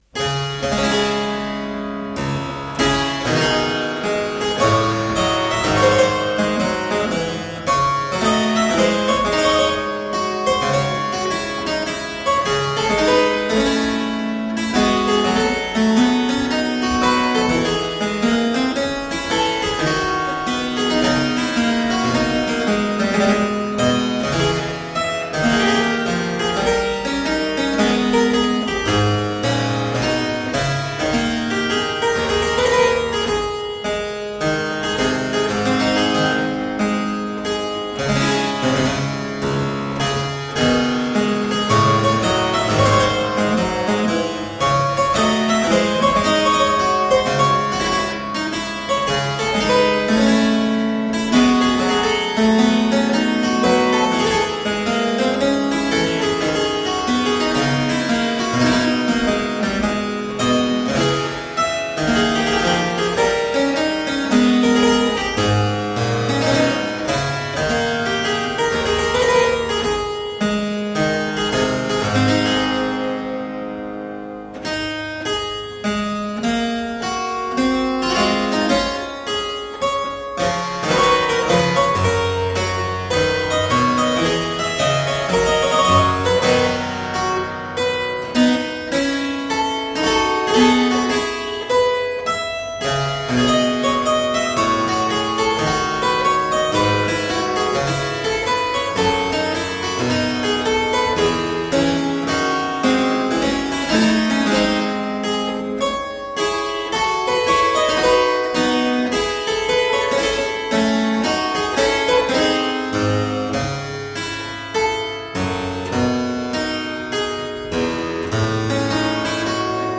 Solo harpsichord music.